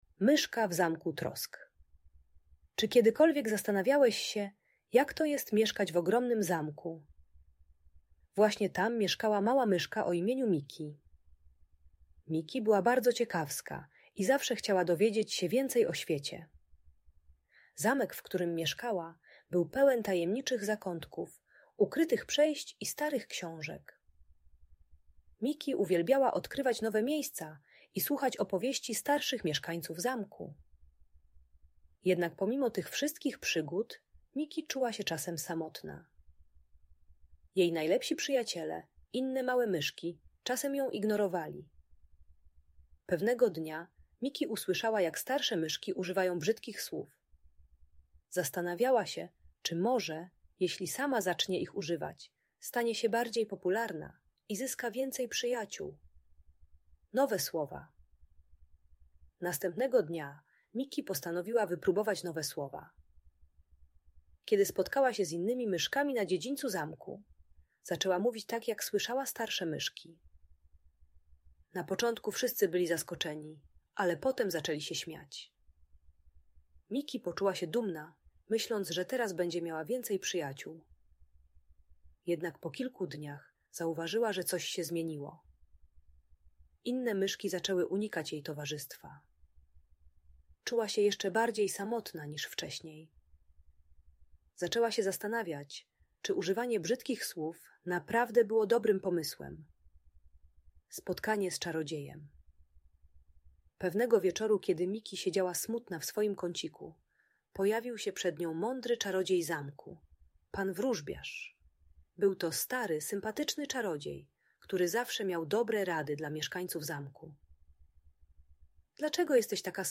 Historia o Myszce w Zamku Trosk - Audiobajka